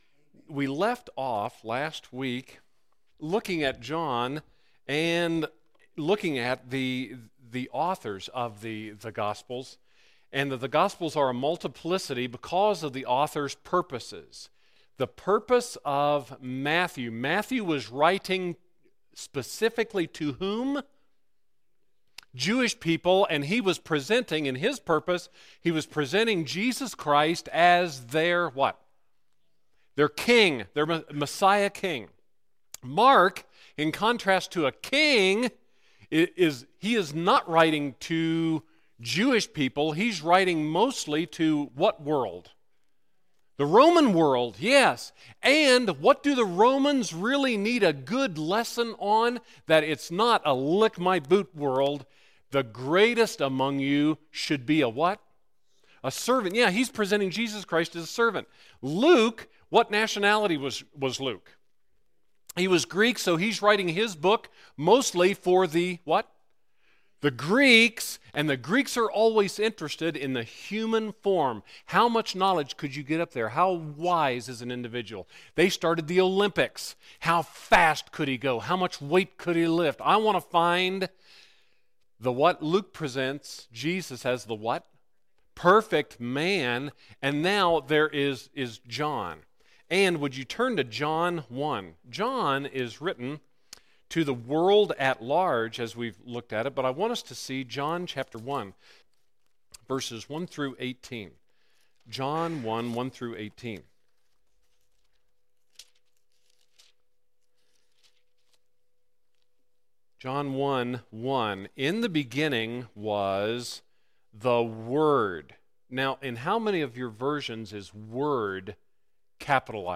Sunday School…